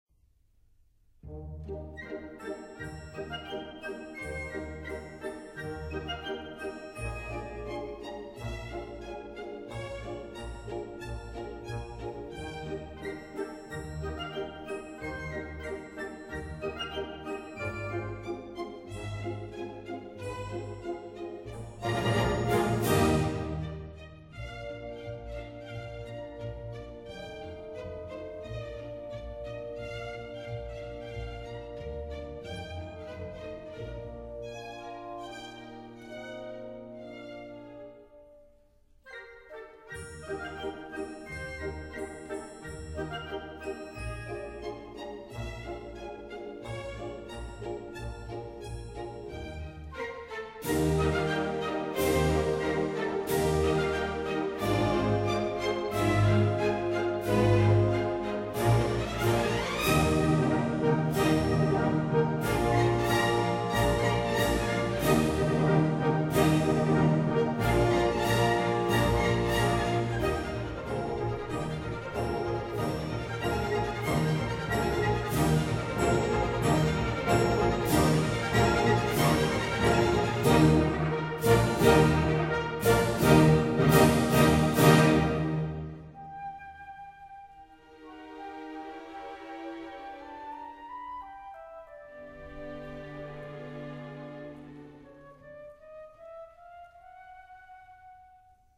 Moderato
中板<01:39>
Sorry, 论坛限制只许上传10兆一下，而且现在激动的网速巨慢，所以只能牺牲品质，为大家介绍一下音乐了。